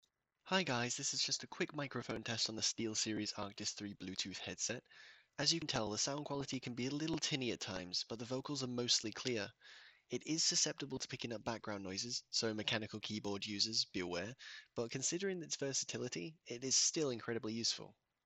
While I'd previously heard that SteelSeries offer quality microphone solutions on the Arctis 5 and Arctis 7, my experience with the Arctis 3 Bluetooth suggests it's a little on the quiet side and is noticeably lacking in bass.
SteelSeries Arctis 3 Bluetooth mic test
I would urge users to be careful about their microphone placement, as the closer it is to the user's mouth, the more their speech will be clouded with breathy bursts. Mechanical keyboards and shuffling will also be picked up as the microphone is fairly sensitive, so overall this isn't the best mic I've ever used.
SteelSeries_Arctis_3_Bluetooth_Microphone.mp3